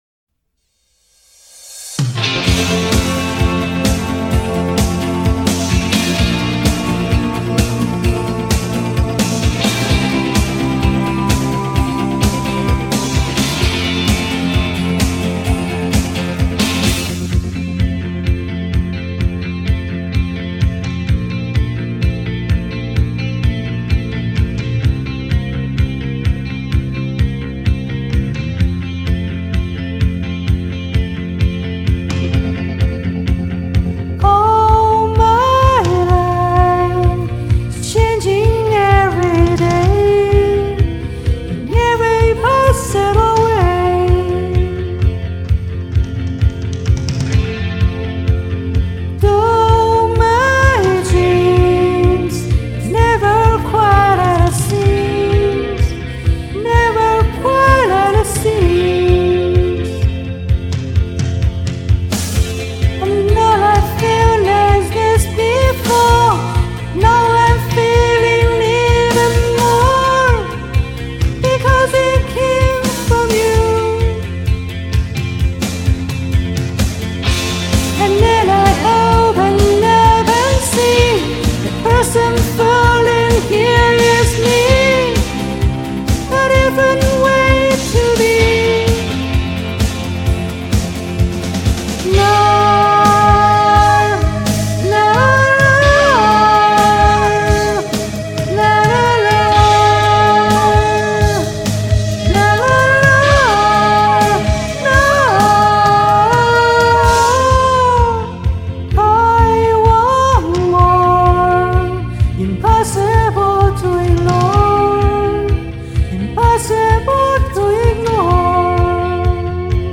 女生的歌你唱得这么妖冶魅惑真的好吗？
• 这声音真清亮。
都是假音整的
还是不会头音，所以声音很闷